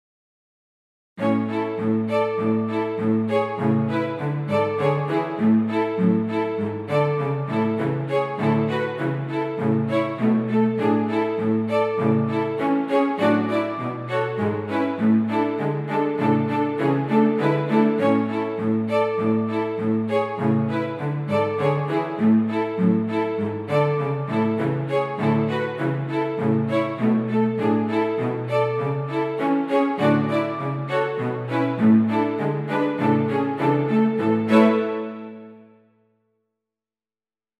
于是我也学习一把，第一次按流行音乐的感觉写了个手机铃声。从乐谱可以看出来，所谓“按流行音乐的感觉”，实际就是滥用和旋的意思。这段音乐本来是可以循环播放周而复始的（这才是铃声嘛），不过处于演示的目的，我还是加上了最后的一个小节，使它有个结尾的感觉。
GarageBand 里的钢琴音色不很理想，所以渲染时候换成了断奏弦乐的音色。
点此下载： piano(ringtone) 断奏音色的缺点就是没法体现四分音符和八分音符的区别，所以如果想听原色的铃声的话，midi 档在此： piano(midi)